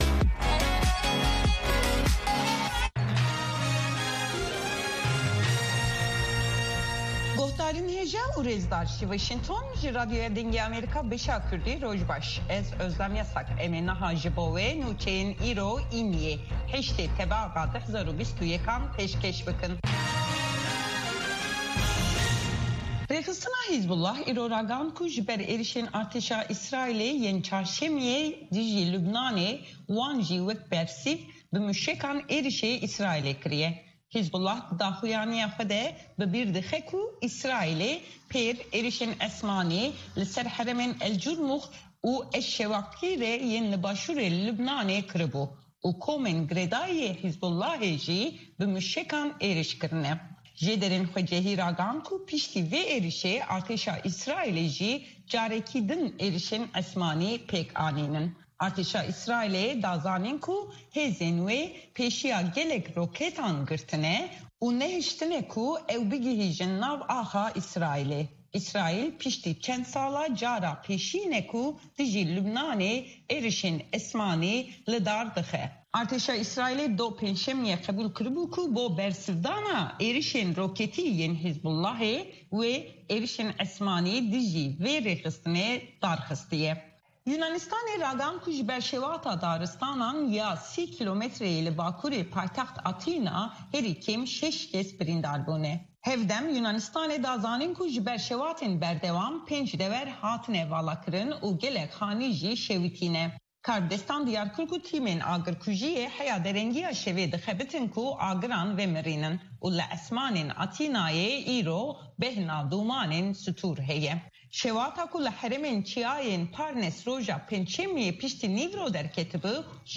هه‌واڵه‌کان، ڕاپۆرت ، وتووێژ